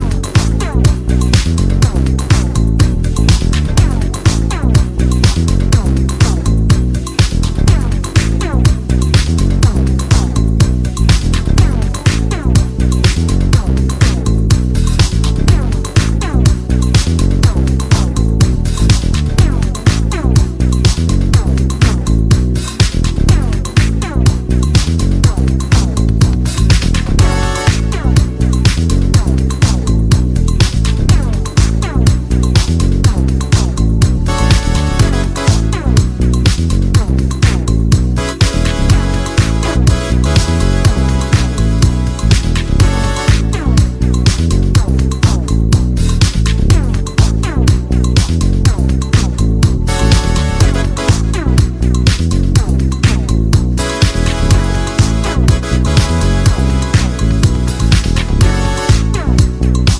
Электронная